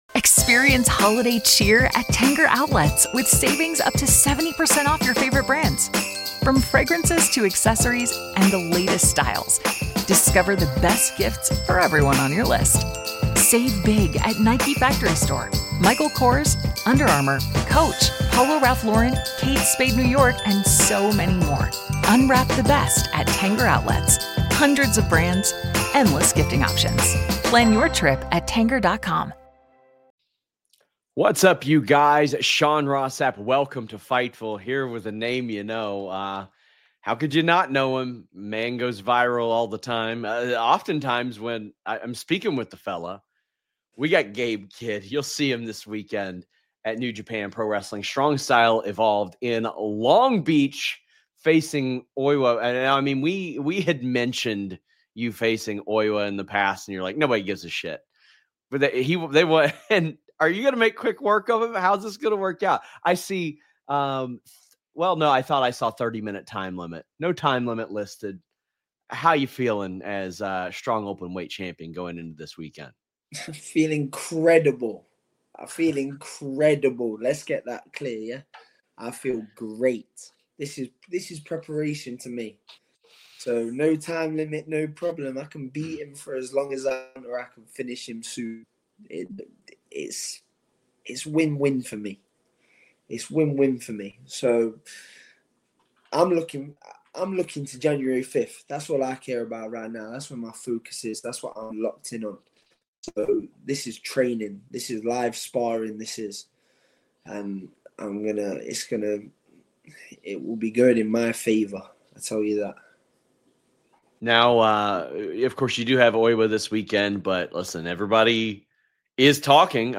Interview | Fightful News